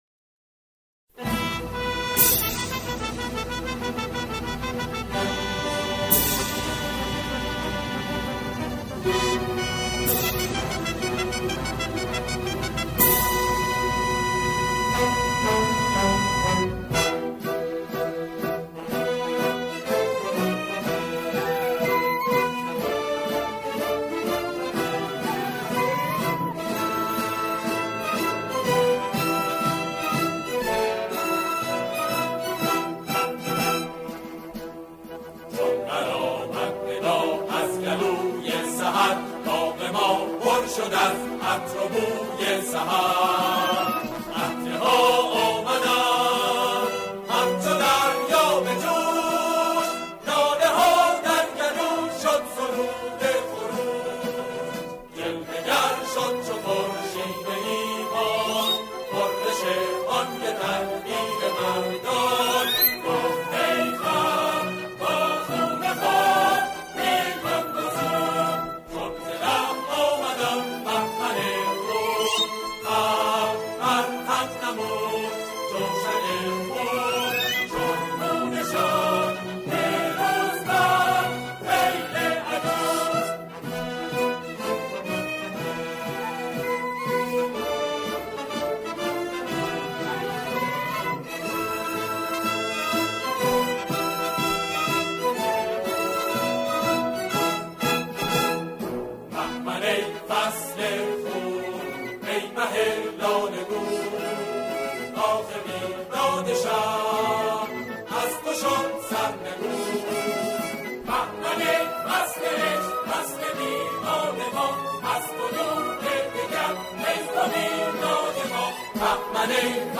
سرودهای دهه فجر
همخوانی